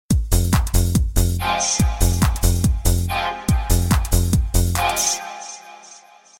Категория: SMS рингтоны | Теги: SMS рингтоны, Dance